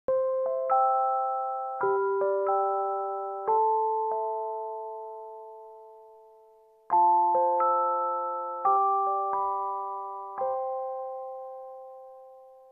Звуки телефона Яндекс
На этой странице собраны стандартные звуки телефона Яндекс: рингтоны, уведомления, системные сигналы из прошивки Amber.
calm